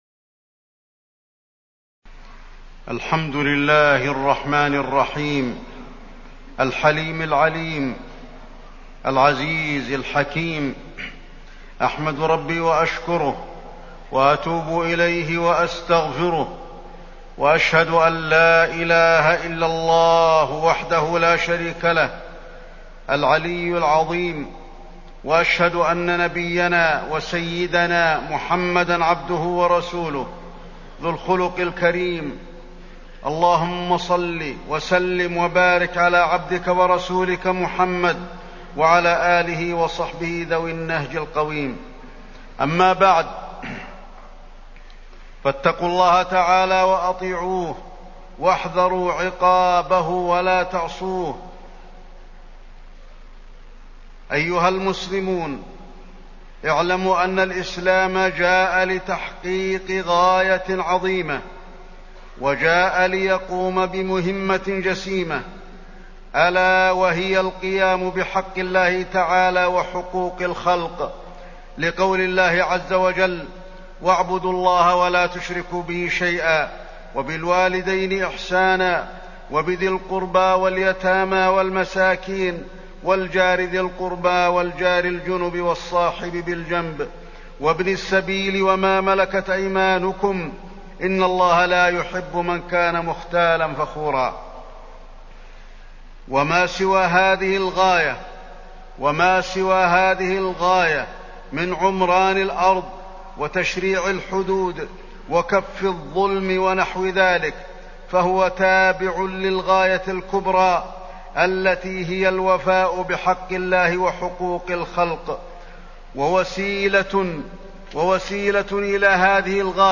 تاريخ النشر ١٨ شعبان ١٤٢٨ هـ المكان: المسجد النبوي الشيخ: فضيلة الشيخ د. علي بن عبدالرحمن الحذيفي فضيلة الشيخ د. علي بن عبدالرحمن الحذيفي فضل حمد الله تعالى